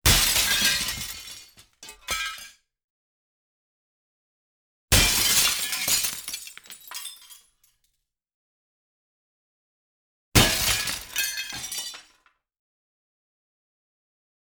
Window Break Sound
household